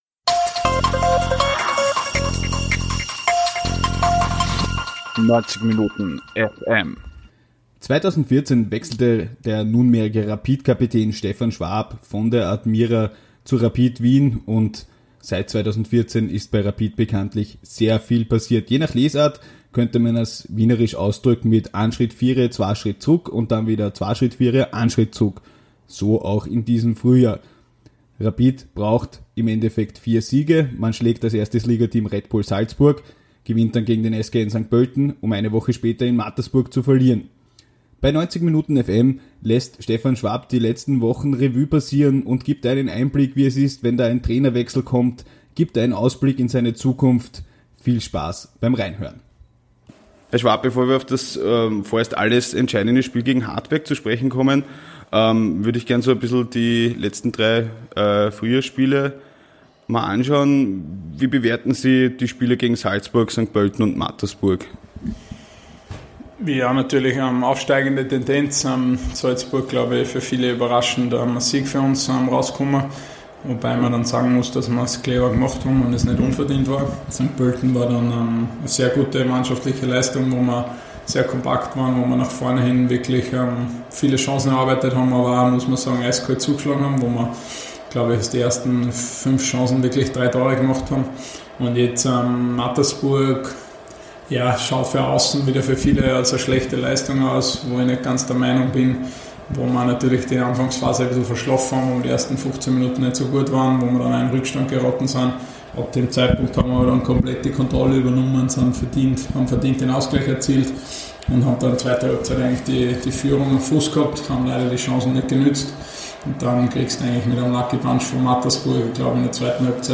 Im 90minutenFM Interview spricht Rapid-Kapitän Stefan Schwab über die Situation vor dem Hartberg-Match, wiederkehrende Rapid-Krisen, die vielen Trainerwechsel und der persönliche Umgang damit und die persönliche Zukunft!